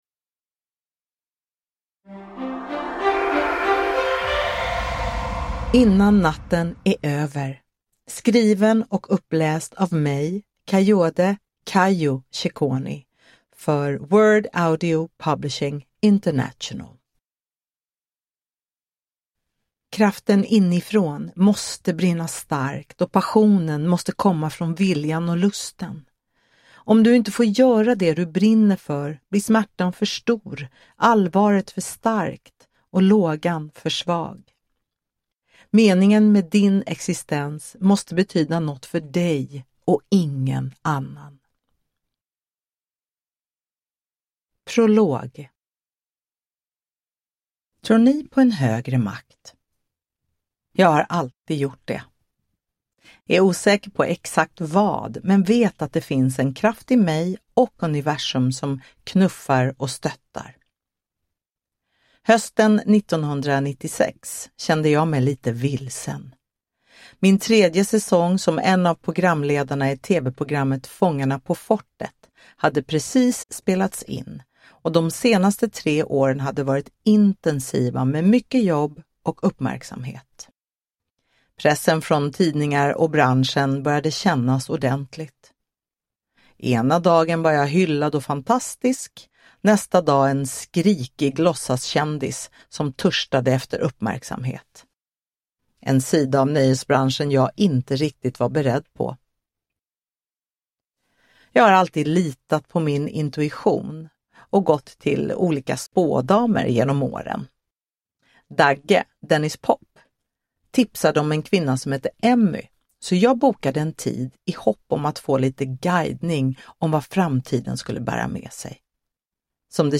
Uppläsare: Kayode "Kayo" Shekoni
Ljudbok